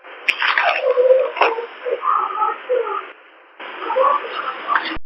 Here's A Few EVP's Featuring Sing Song Voices & Music
We hear this pretty clearly. It appears to be the voice of a young boy or possibly a girl. The second portion of this clip isn't as clear but we hear, "I'm watching" or possibly "I'm walking" followed by a male voice saying "I'm watching".